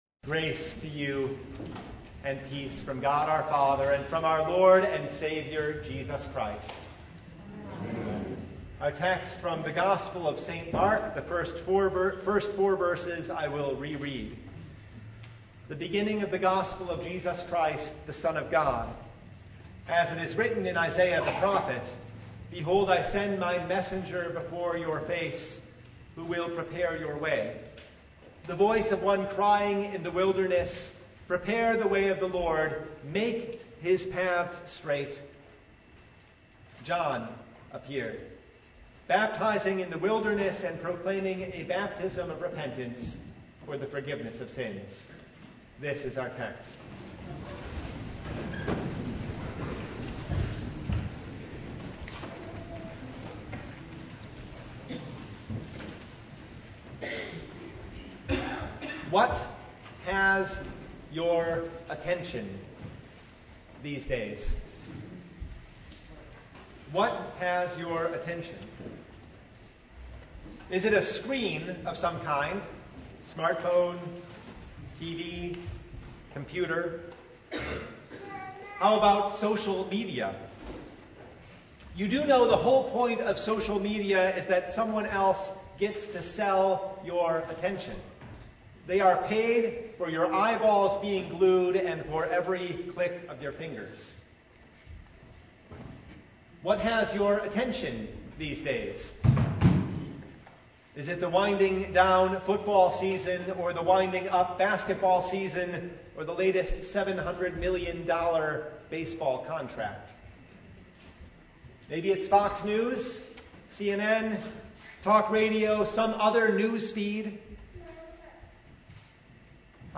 Sermon from Second Sunday in Advent (2023)
Sermon Only